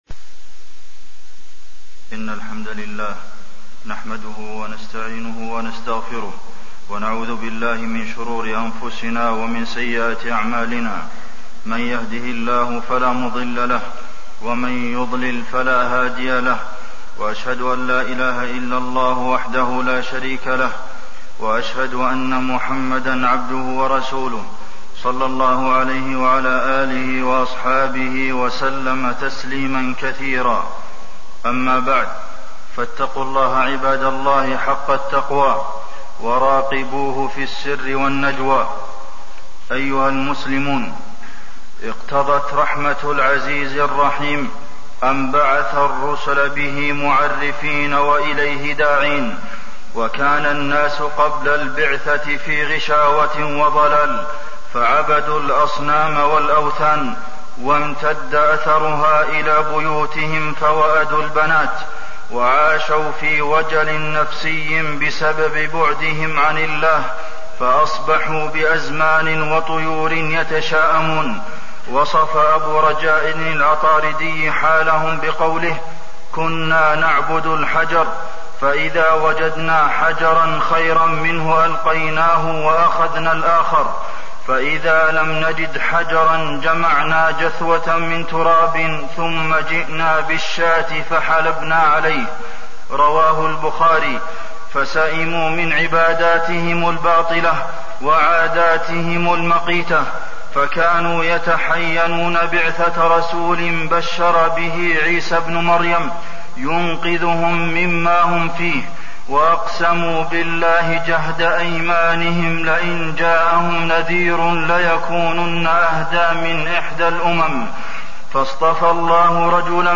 تاريخ النشر ٩ جمادى الأولى ١٤٣١ هـ المكان: المسجد النبوي الشيخ: فضيلة الشيخ د. عبدالمحسن بن محمد القاسم فضيلة الشيخ د. عبدالمحسن بن محمد القاسم محمد صلى الله عليه وسلم